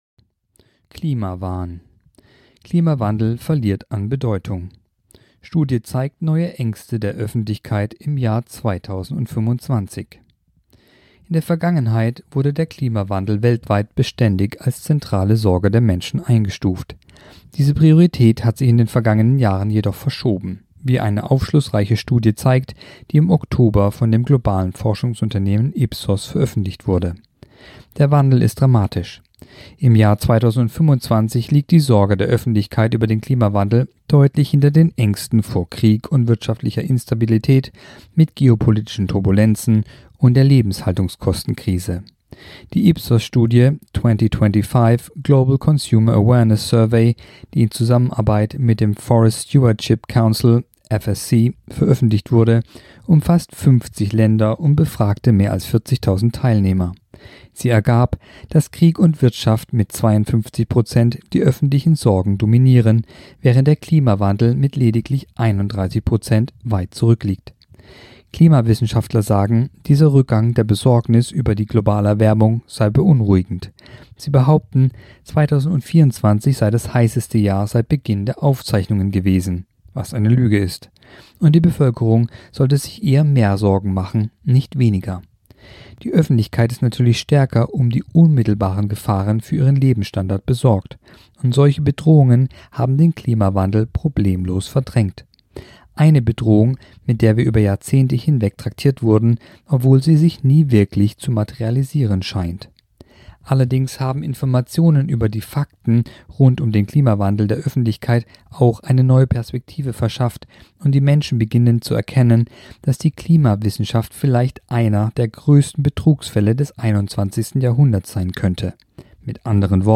Kolumne der Woche (Radio)Klimawandel verliert an Bedeutung